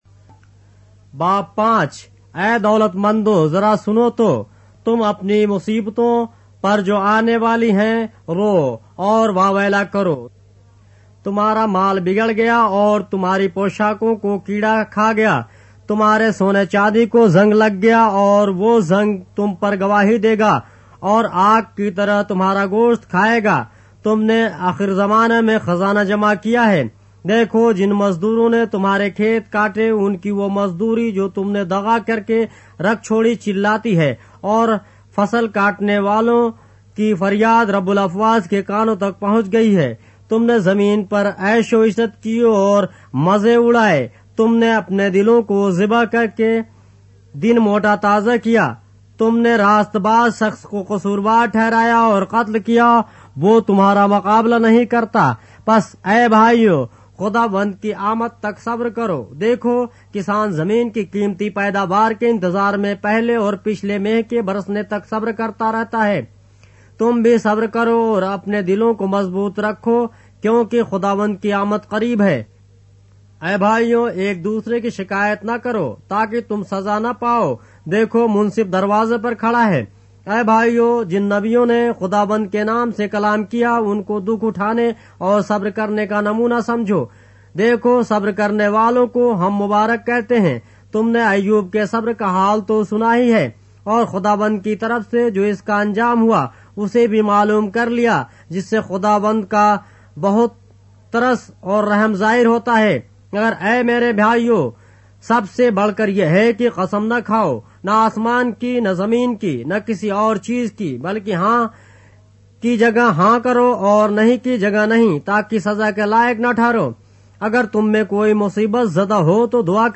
اردو بائبل کے باب - آڈیو روایت کے ساتھ - James, chapter 5 of the Holy Bible in Urdu